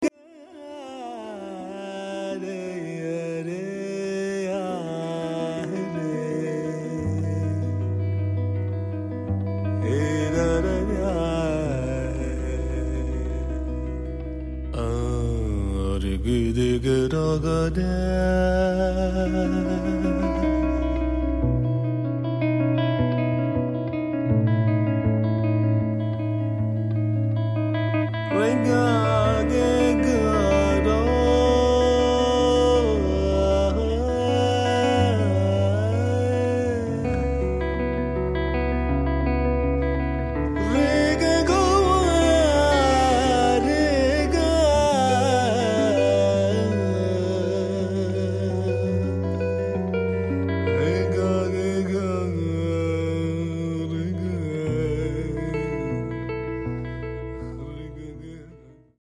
voice, oud
guitars, violin, programmino, fender rhodes guitar
trumpet, flugelhorn
su un tappeto di sonorità elettronicamente raffinate.